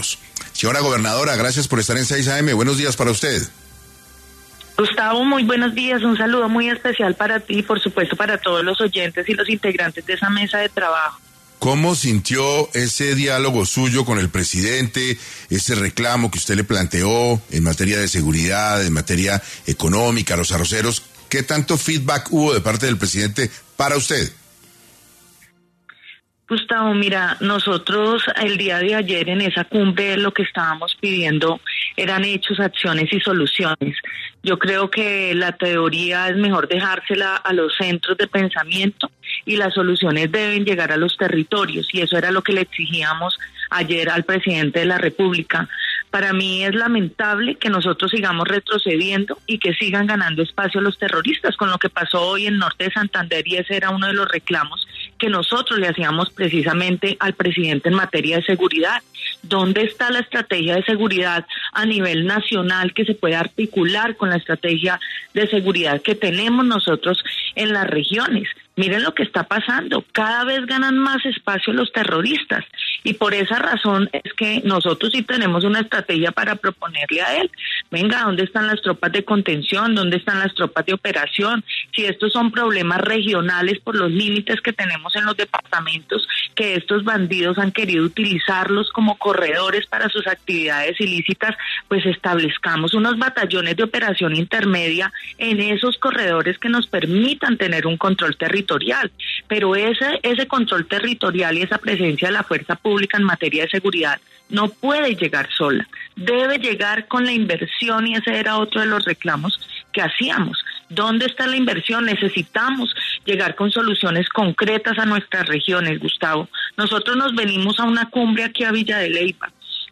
En diálogo con 6AM de Caracol Radio, la gobernadora Matiz manifestó que, para el Tolima no se lleva ninguna respuesta concreta de la intervención de Petro en el primer día de la Cumbre: